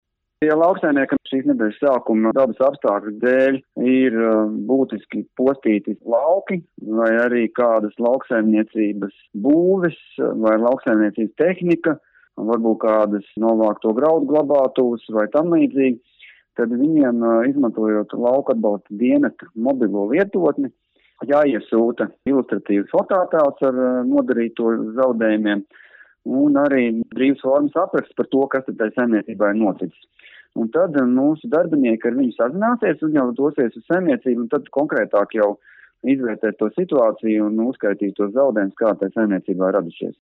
RADIO SKONTO Ziņās par vētrā cietušo lauksaimnieku apzināšanu